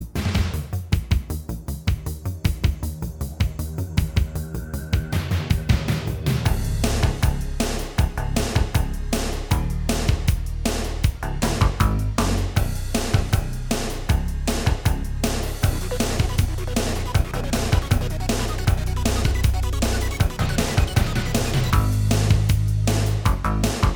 Minus All Guitars Soundtracks 3:30 Buy £1.50